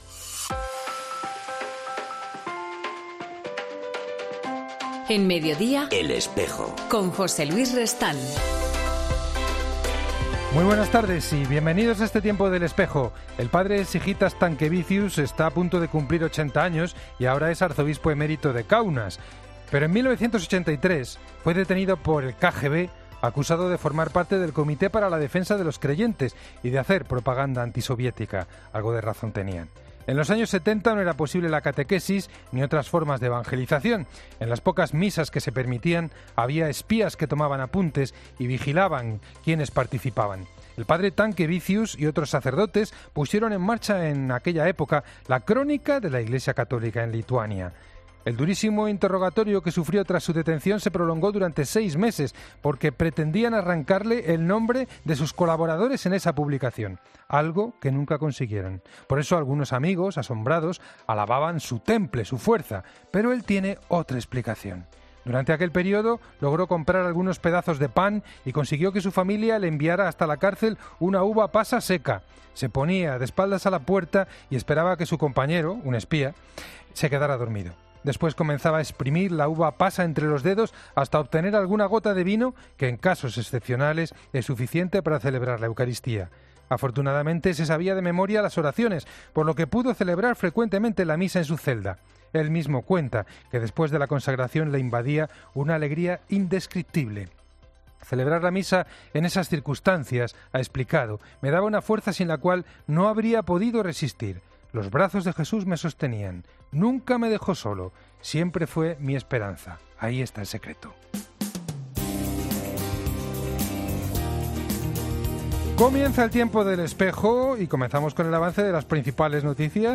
En El Espejo del 24 de septiembre hablamos con Mons. Philippe Jourdan, obispo de Estonia.